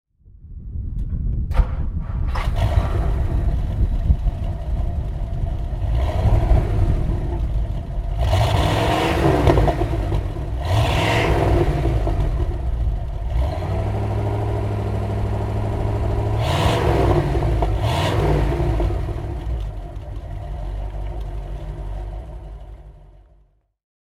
Edsel Ranger (1958) - Starten und Leerlauf
Edsel_Ranger_1958.mp3